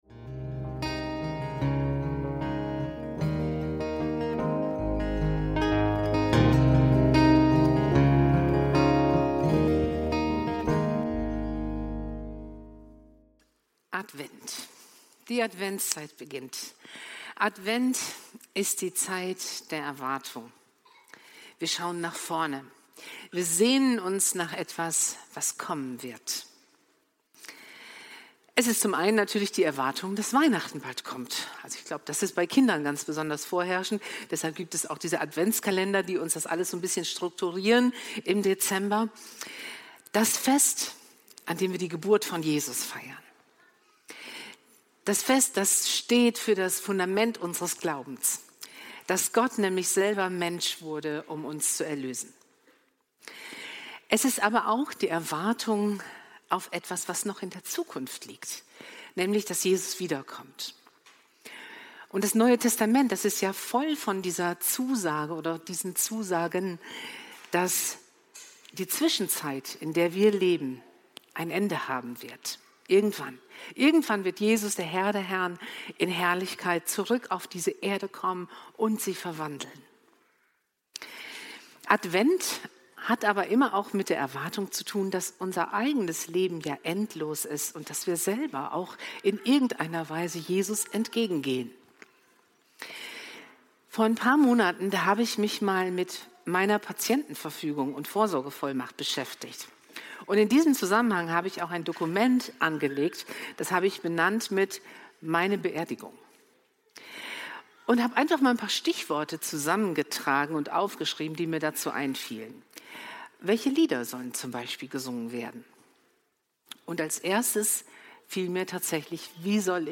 Wie soll ich dich empfangen? – Predigt vom 30.11.2025